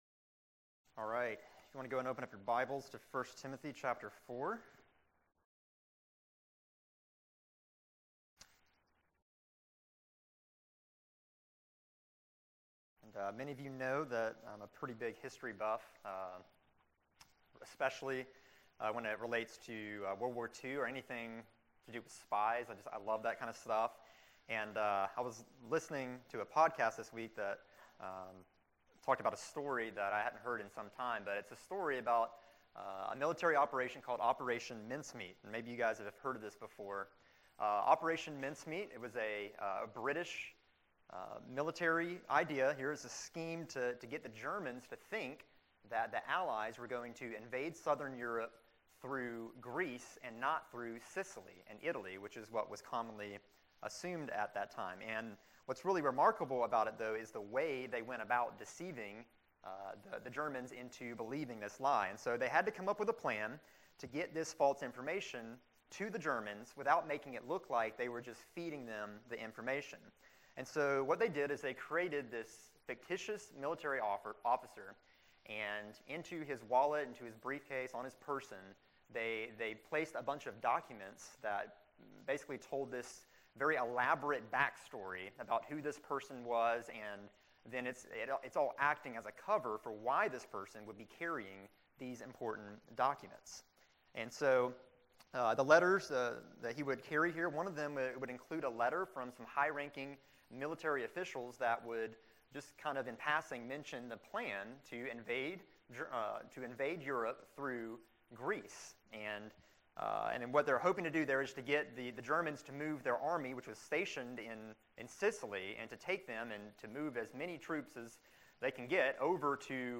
February 28, 2016 Morning Worship | Vine Street Baptist Church
This was the next message in multi-part sermon series on the book of 1st Timothy.